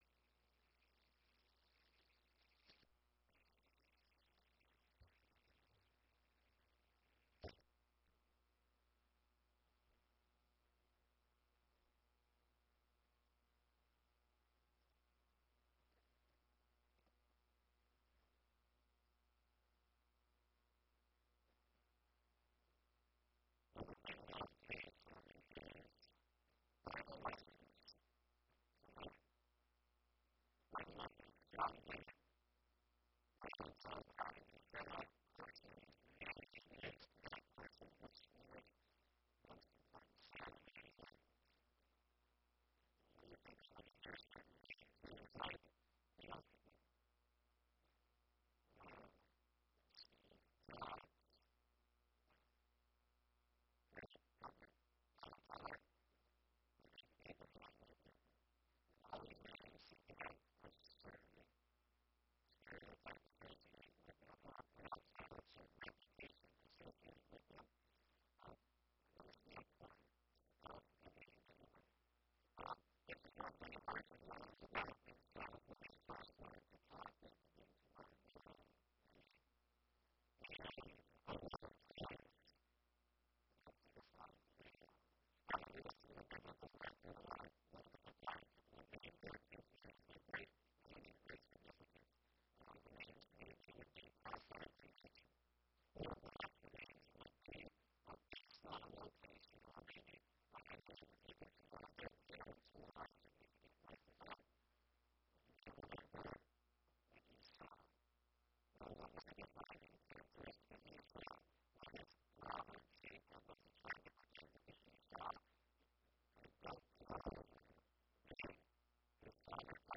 Given in Sioux Falls, SD Watertown, SD